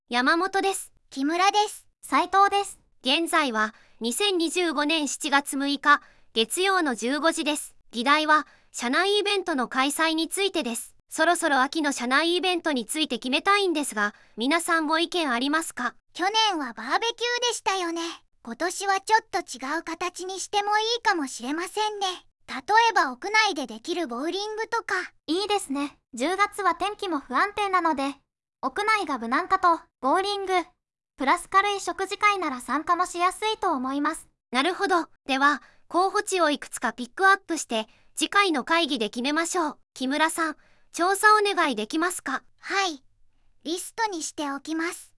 VOICEVOX活用